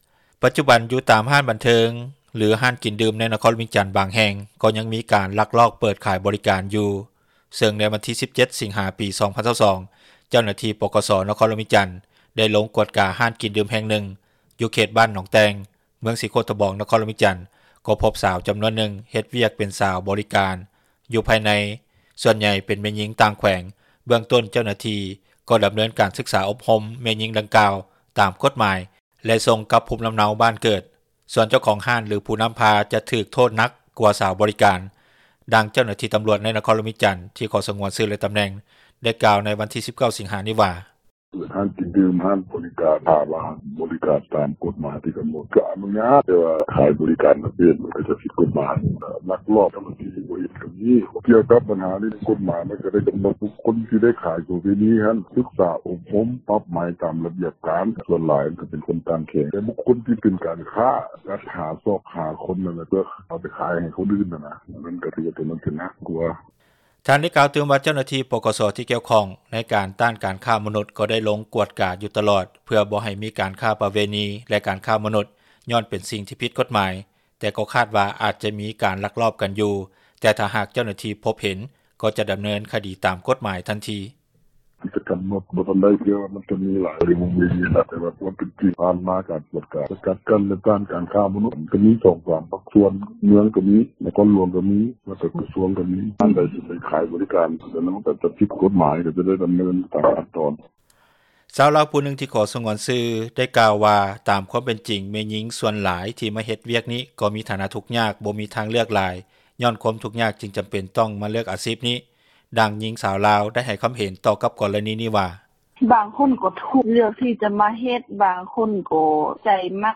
ດັ່ງເຈົ້າໜ້າທີ່ຕຳຣວດ ໃນນະຄອນຫຼວງວຽງຈັນ ທີ່ຂໍສງວນຊື່ ແລະຕຳແໜ່ງ ໄດ້ກ່າວໃນວັນທີ 19 ສິງຫານີ້ວ່າ: